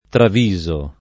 travisare v.; traviso [ trav &@ o ]